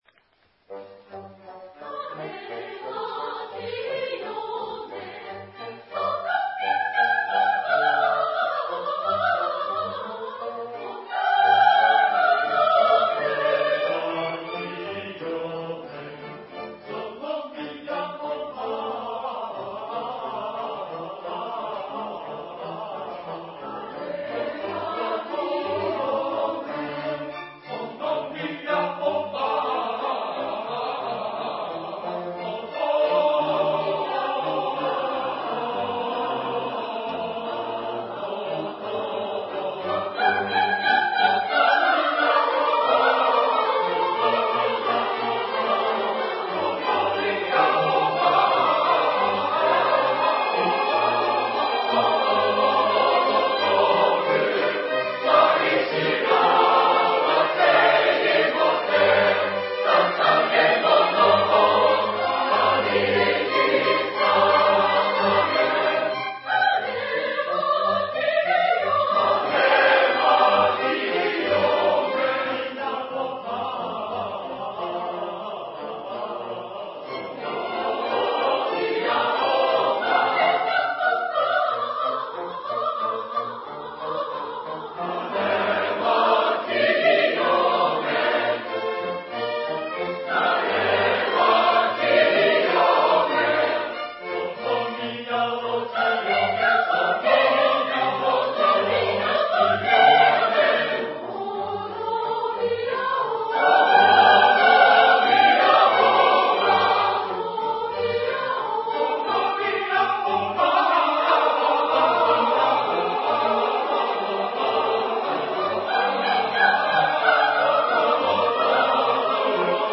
吉祥寺2002年公演録音